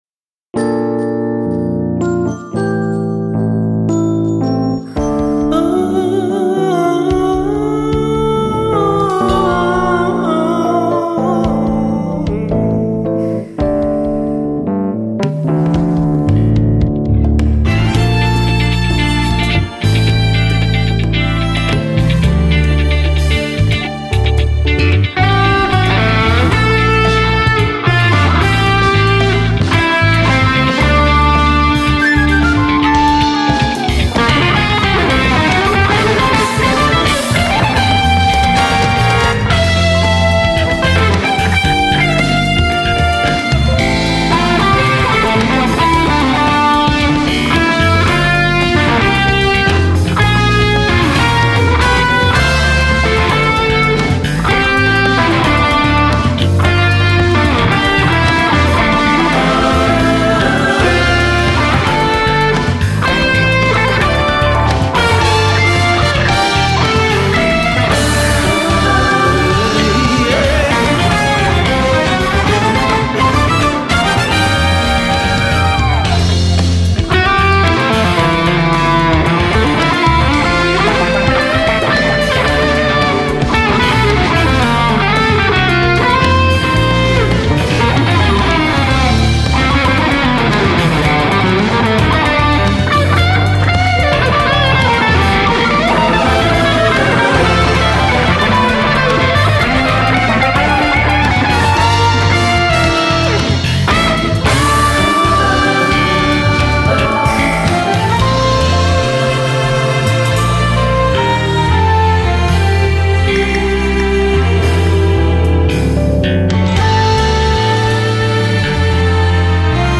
傾向 　フュージョン